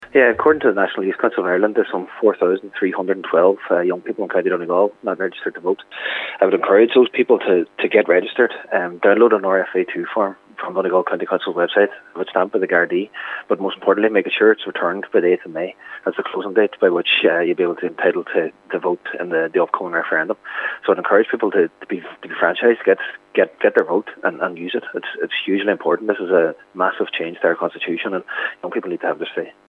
The closing date for voting registration is next week, May 8th, Cathaoirleach of the Inishowen Municipal District, Cllr Jack Murray is encouraging everyone who is registered to do so: